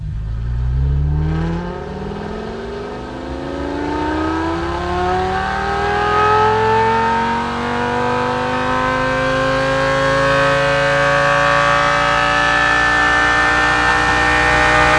rr3-assets/files/.depot/audio/Vehicles/v10_01/v10_01_Accel.wav
v10_01_Accel.wav